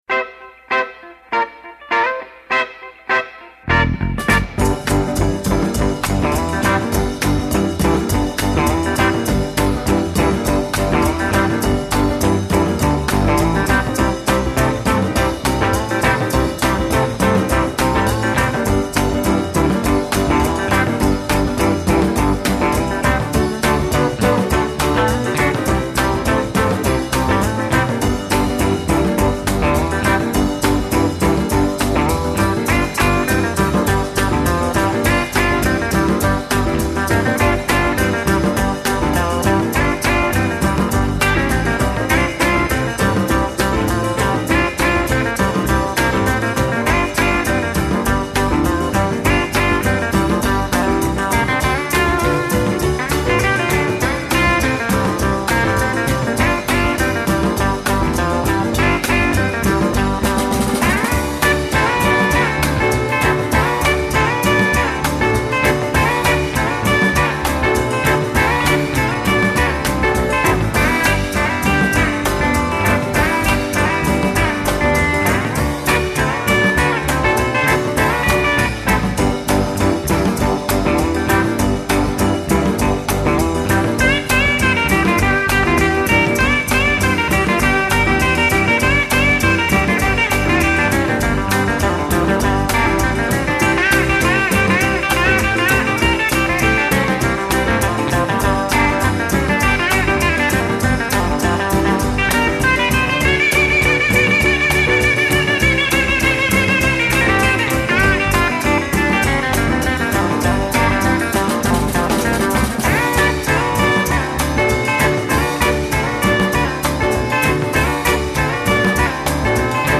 stereo version
INSTR